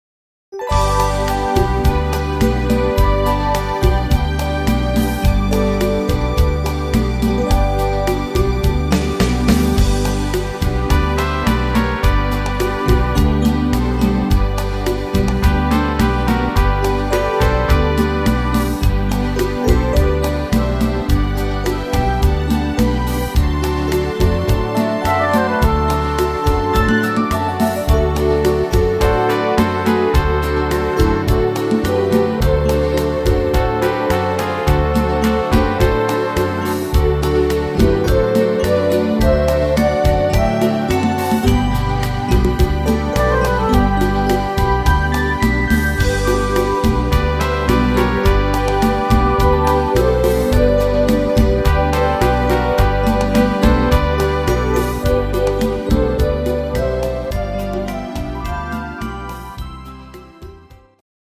instr. Orgel